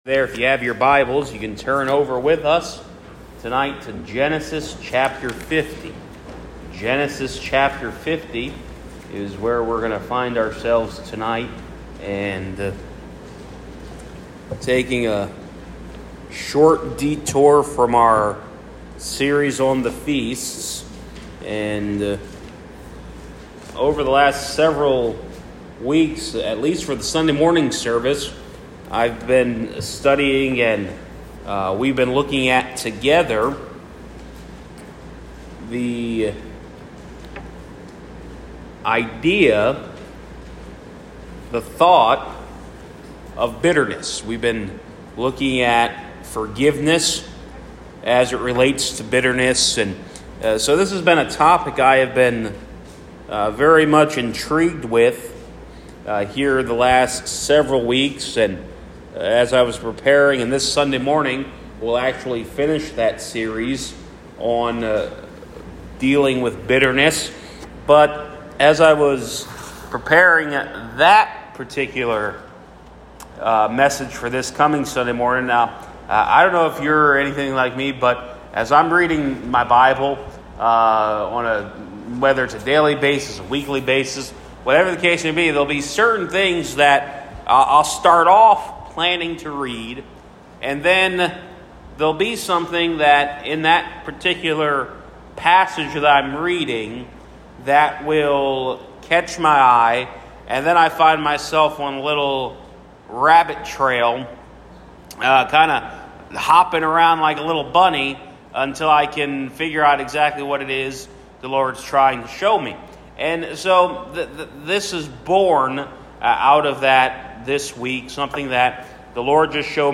Sermons | First Baptist Church of Sayre, PA